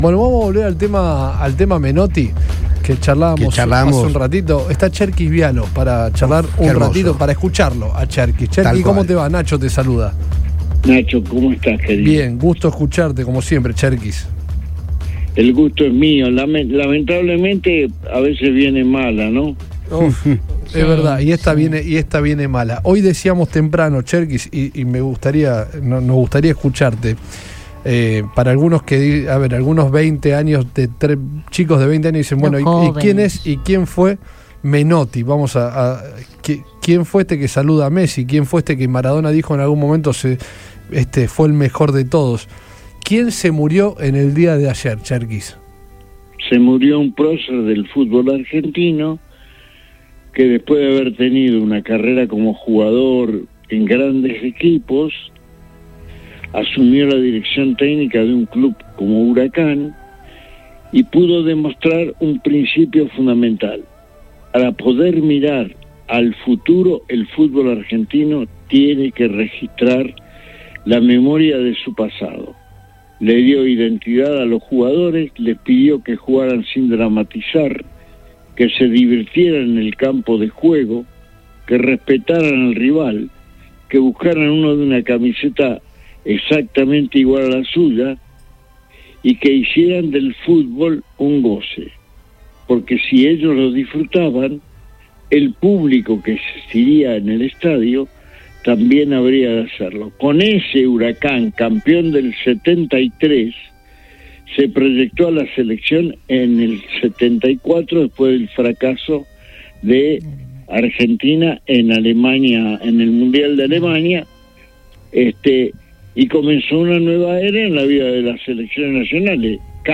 En Dialogo con el equipo de Todo Pasa de Radio Boing el experimentado periodista deportivo, Ernesto Cherquis Bialo compartió un repaso sobre lo que significó la trayectoria de César Luis Menotti, el ex entrenador de la Selección Argentina de Fútbol que falleció este domingo a los 85 años.